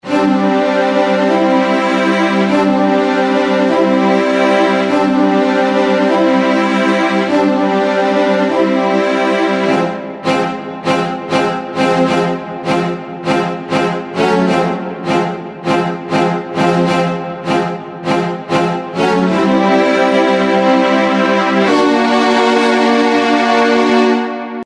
HALion6 : Studio Strings